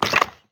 Minecraft Version Minecraft Version latest Latest Release | Latest Snapshot latest / assets / minecraft / sounds / mob / wither_skeleton / step3.ogg Compare With Compare With Latest Release | Latest Snapshot
step3.ogg